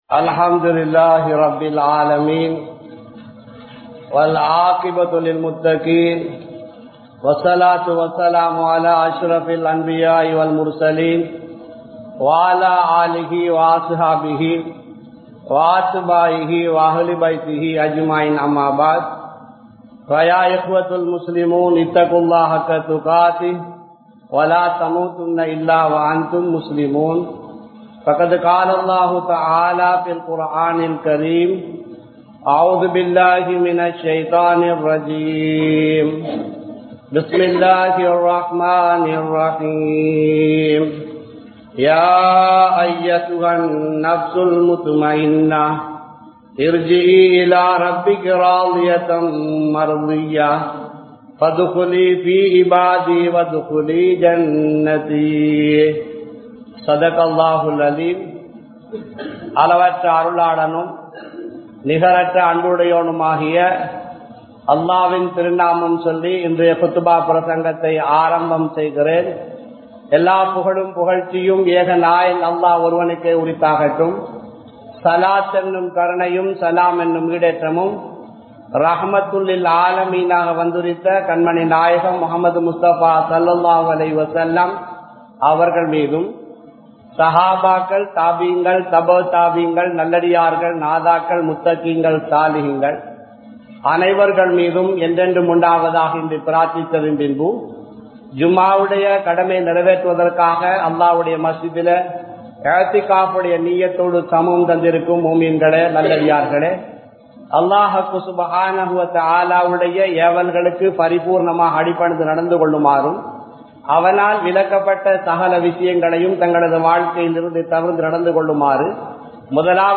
Aalamul Arvah il Roohin Nilai (ஆலமுல் அர்வாஹில் றூஹின் நிலை) | Audio Bayans | All Ceylon Muslim Youth Community | Addalaichenai